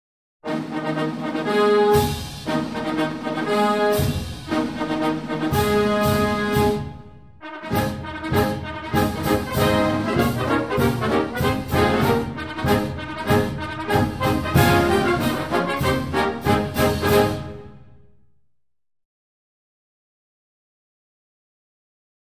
three-ruffles-and-flourishes-flag-officers-march-1.mp3